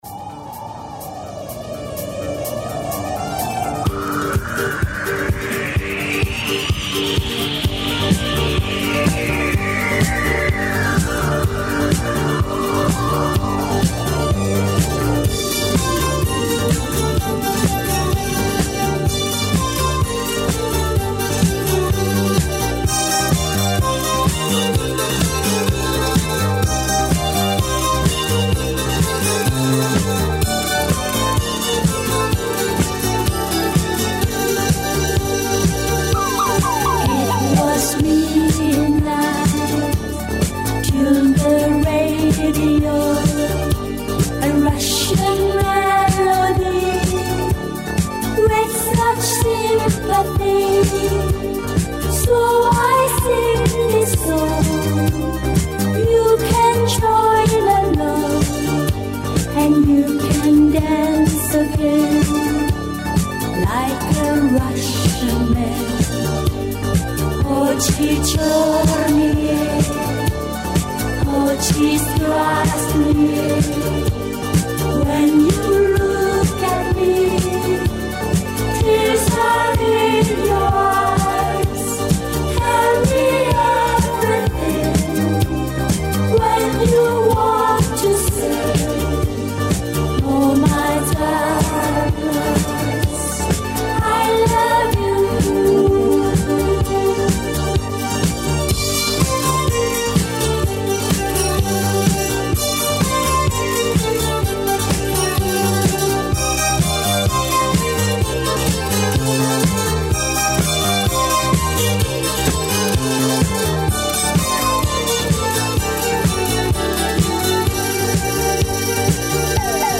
Получше качество.